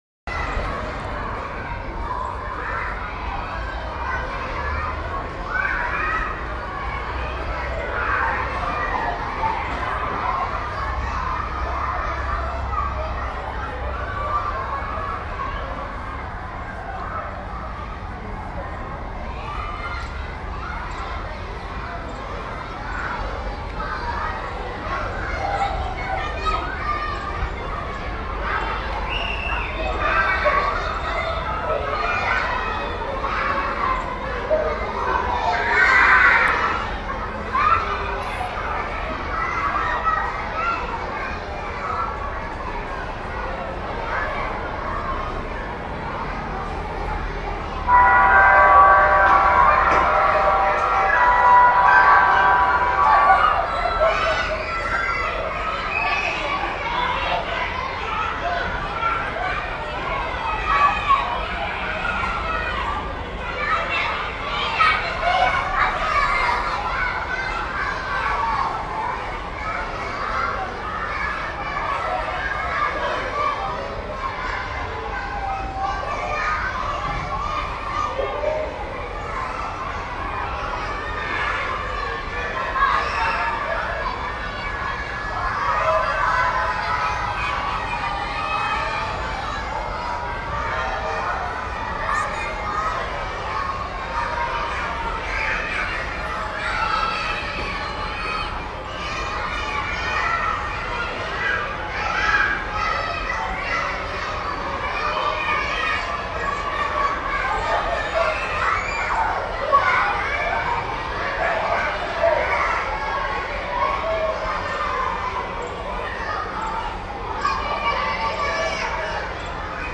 دانلود صدای بازی کردن بچه ها در پارک و حیاط کوچه از ساعد نیوز با لینک مستقیم و کیفیت بالا
جلوه های صوتی